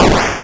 ihob/Assets/Extensions/explosionsoundslite/sounds/bakuhatu102.wav at master
bakuhatu102.wav